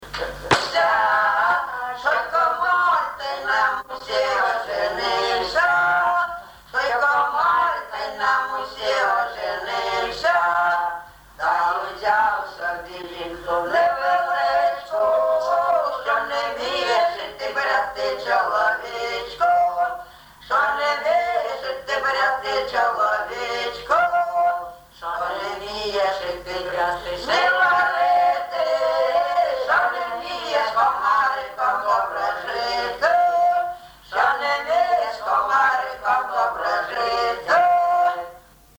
ЖанрЖартівливі
Місце записум. Єнакієве, Горлівський район, Донецька обл., Україна, Слобожанщина